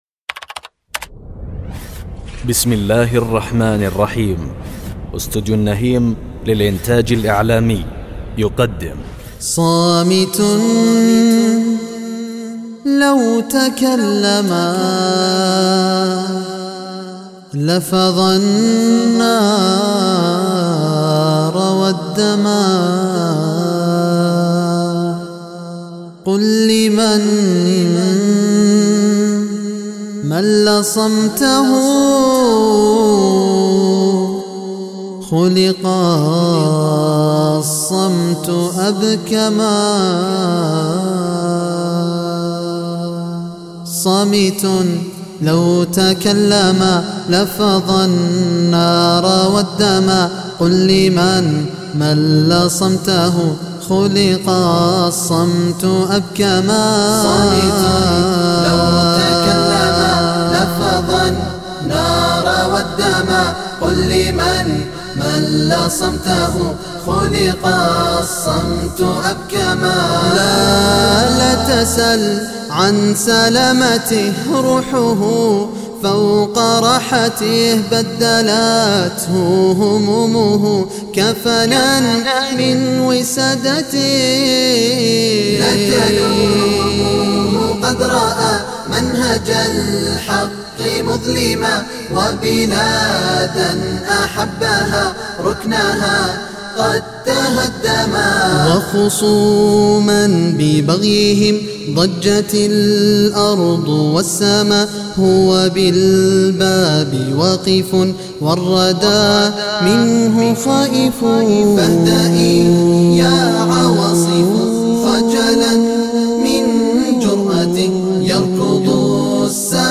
أعمال سابقة لي مع المنشد :